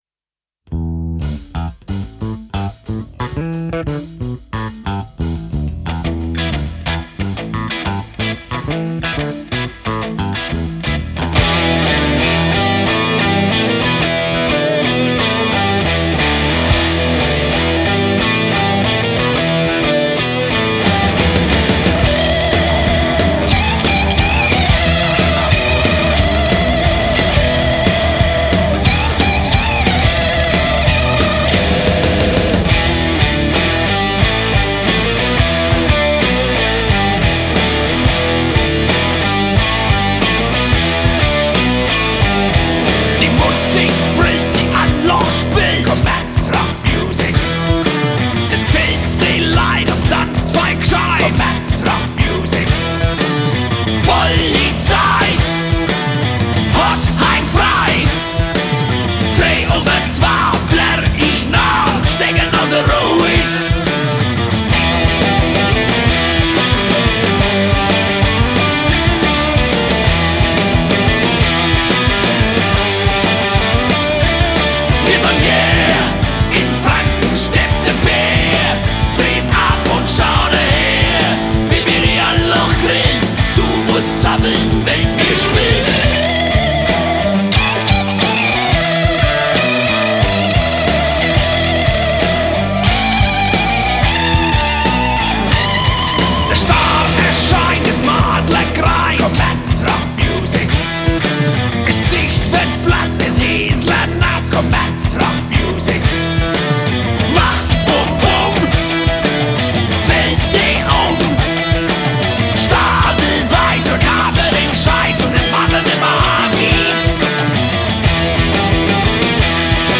Genre: Rock.